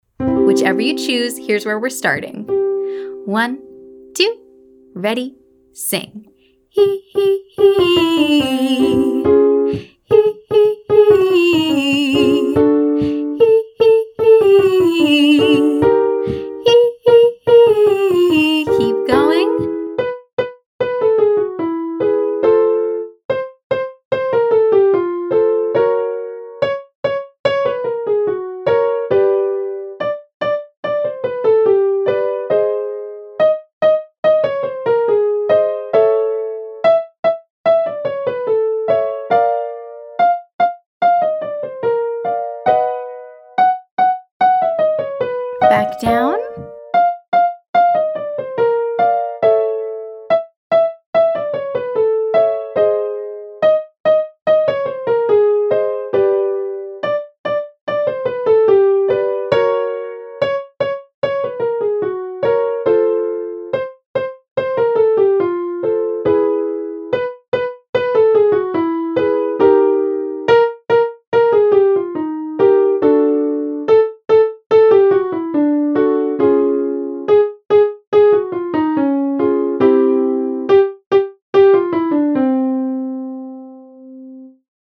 For the first exerccise, starting with that same staccato articulation from our warmup.
Vocal Agility Lesson 3A